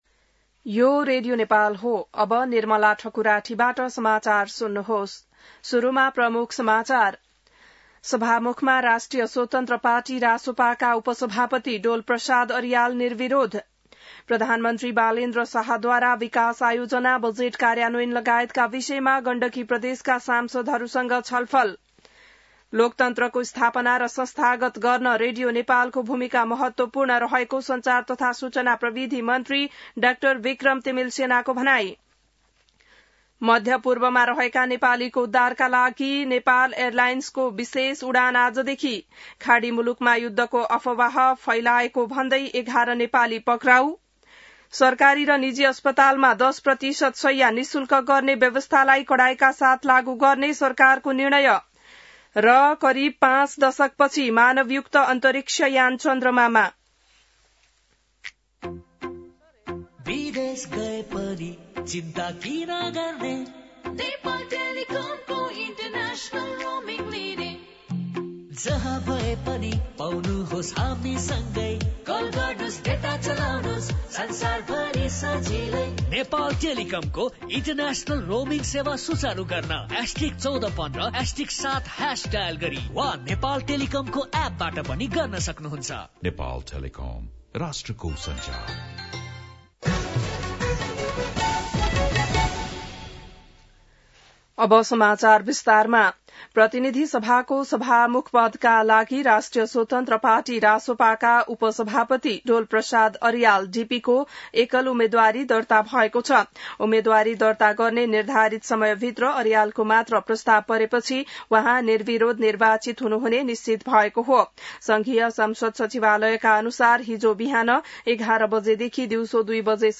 बिहान ७ बजेको नेपाली समाचार : २१ चैत , २०८२